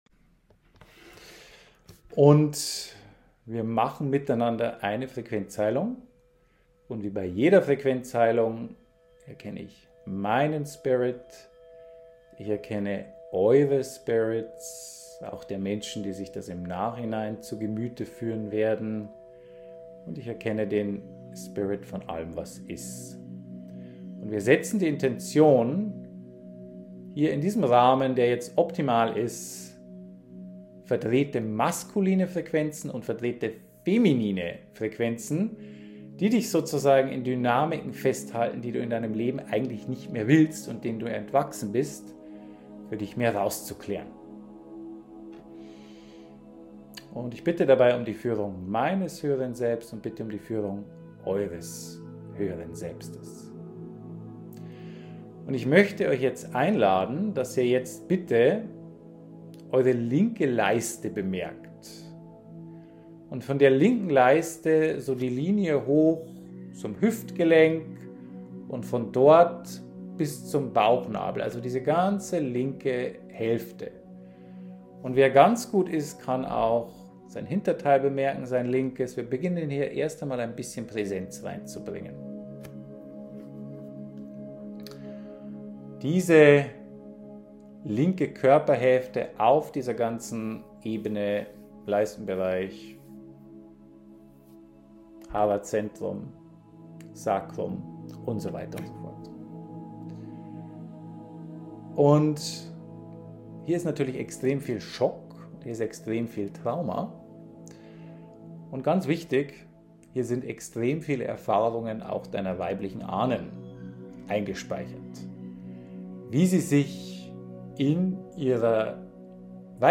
Ma--nnlich_weiblich_MITMUSIK_mp3.mp3